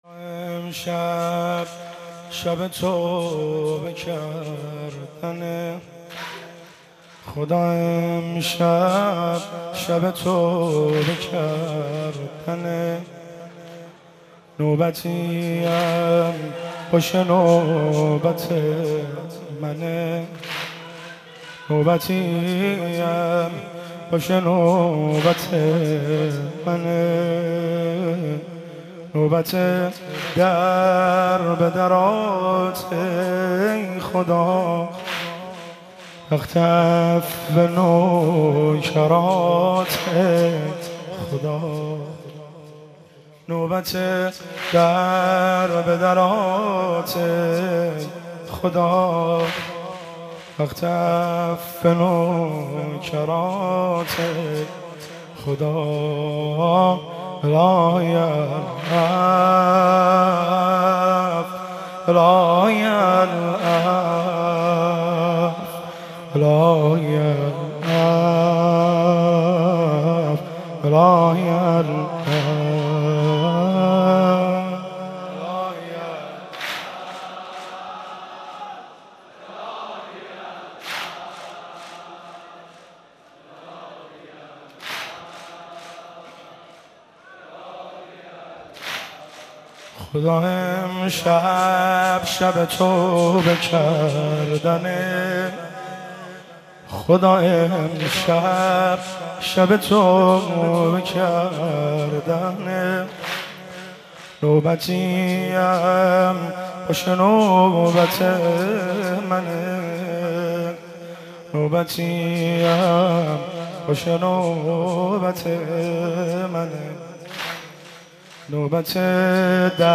مناسبت : شب بیست و سوم رمضان - شب قدر سوم